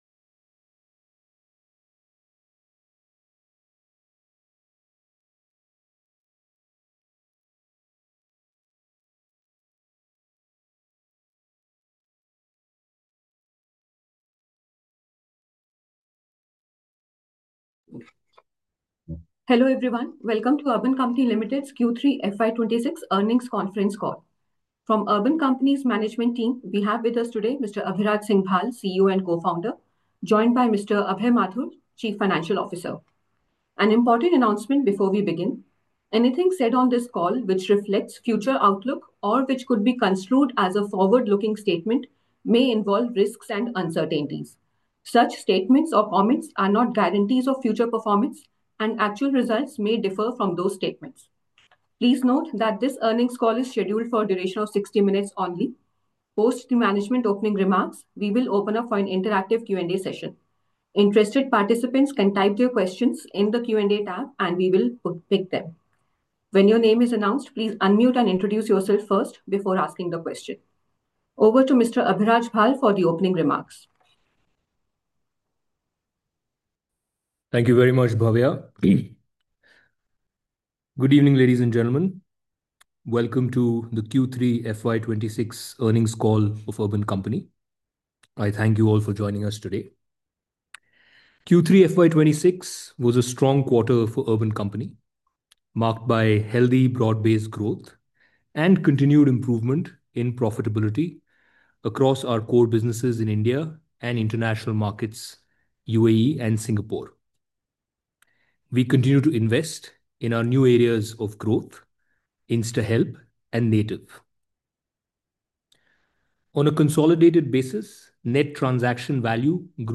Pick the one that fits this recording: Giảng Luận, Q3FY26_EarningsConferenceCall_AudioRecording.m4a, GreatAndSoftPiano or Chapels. Q3FY26_EarningsConferenceCall_AudioRecording.m4a